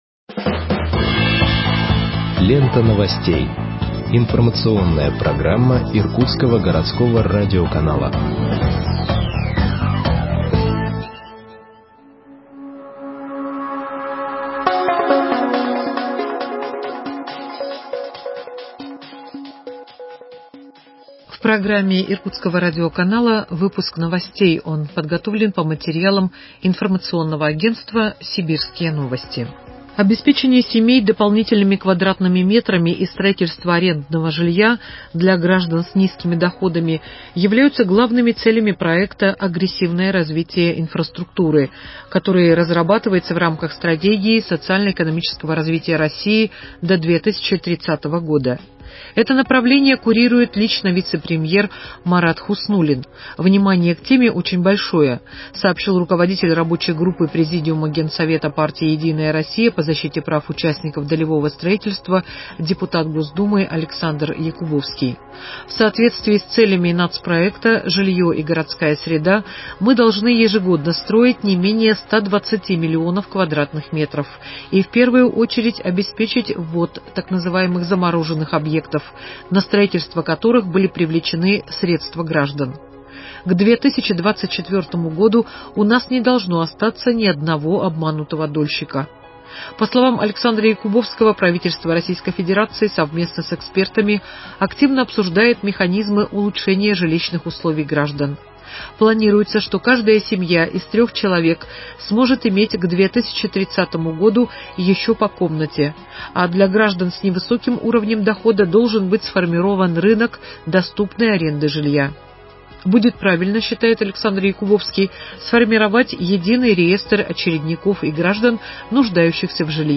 Выпуск новостей в подкастах газеты Иркутск от 21.04.2021 № 1